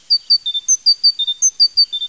1 channel
00212_Sound_succes.mp3